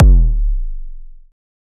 EDM Kick 21.wav